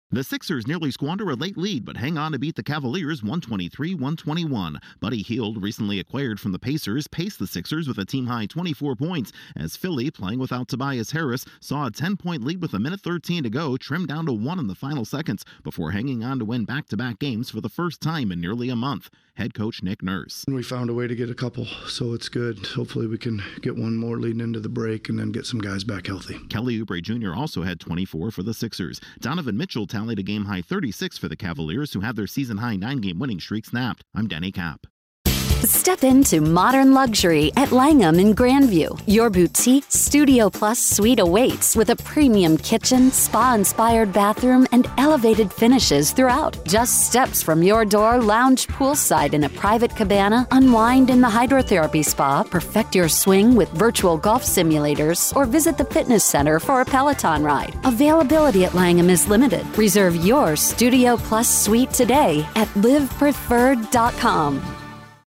The Sixers upend the Cavaliers to end the league's longest active win streak. Correspondent